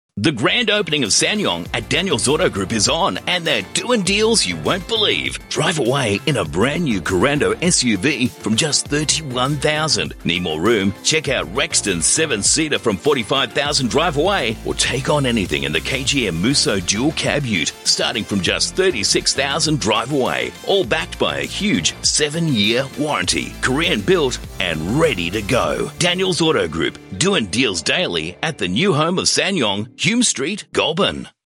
reliable voice actor who excels with a classic, mid-Atlantic narration style, from authoritative, soothing tones to flashy, energetic commercials.